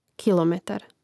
Rastavljanje na slogove: ki-lo-me-tar